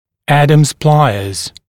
[‘ædəmz ‘plaɪəz][‘эдэмз ‘плайэз]щипцы Адамса